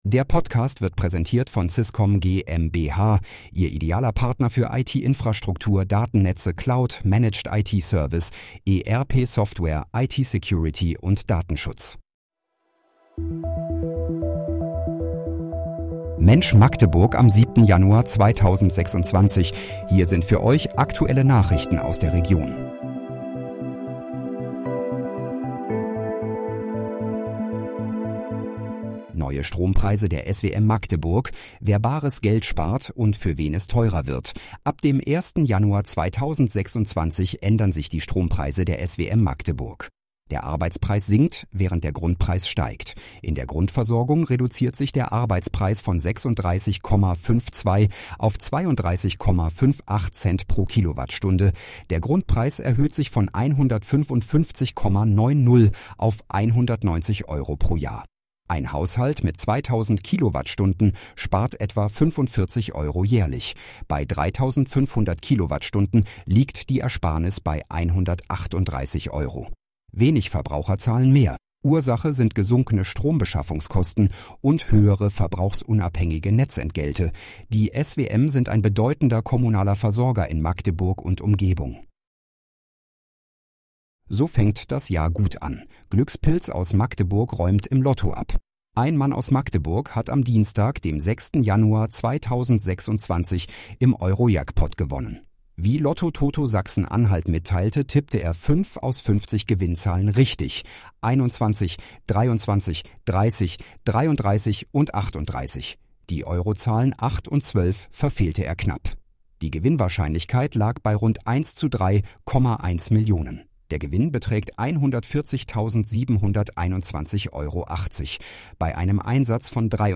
Mensch, Magdeburg: Aktuelle Nachrichten vom 07.01.2026, erstellt mit KI-Unterstützung